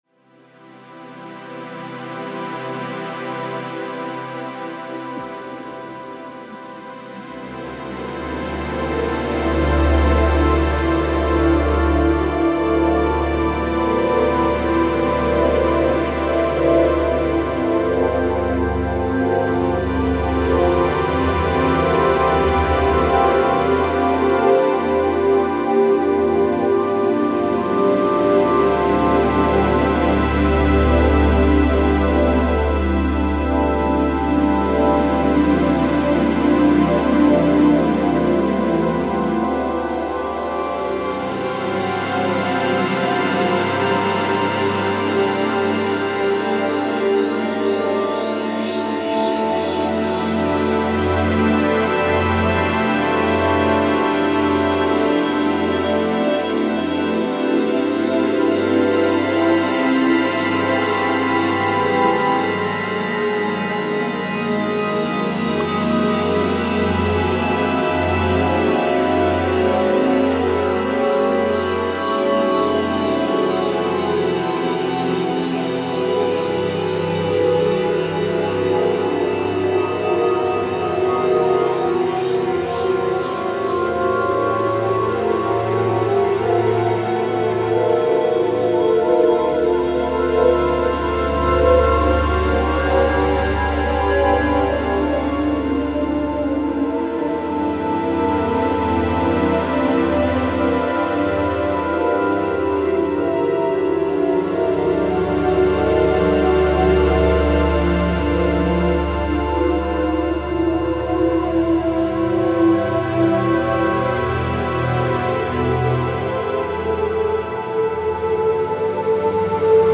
Genre: sound installation / sound art.